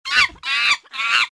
houzi.mp3